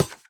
Minecraft Version Minecraft Version snapshot Latest Release | Latest Snapshot snapshot / assets / minecraft / sounds / block / trial_spawner / step3.ogg Compare With Compare With Latest Release | Latest Snapshot